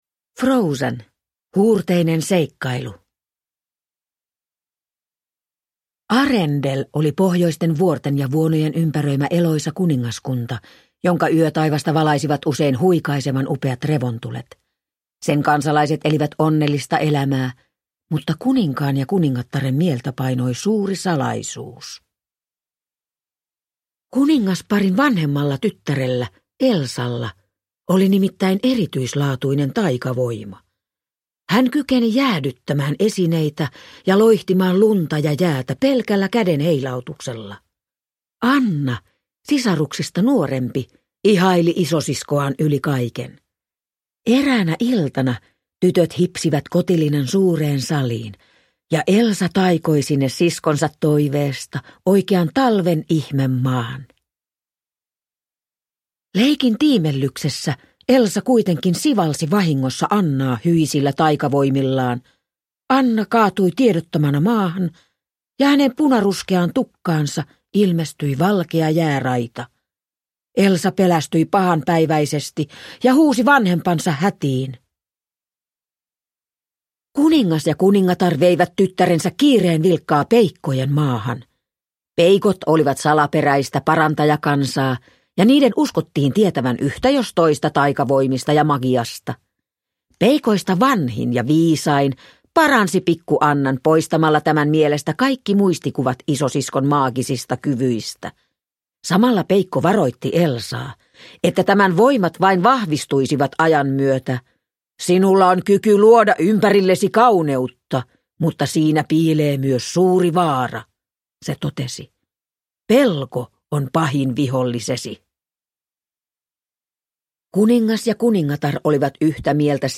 Frozen, Huurteinen seikkailu – Ljudbok – Laddas ner